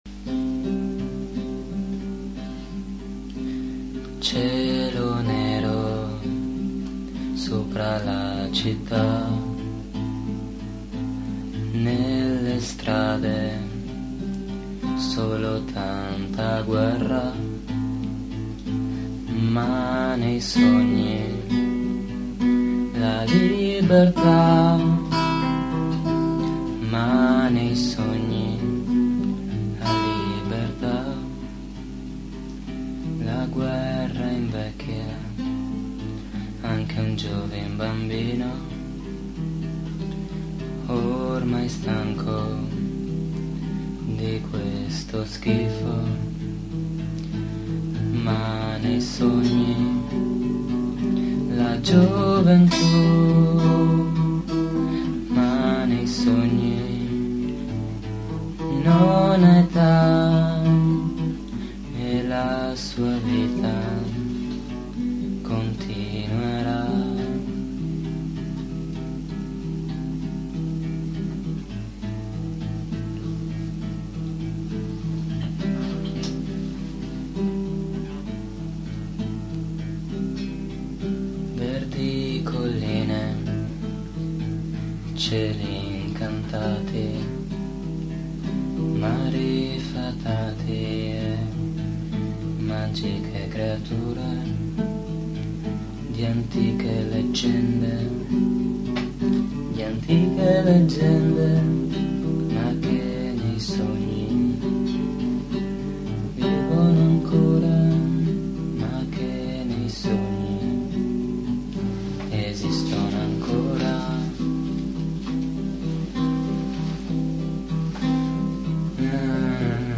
Versione originale unplugged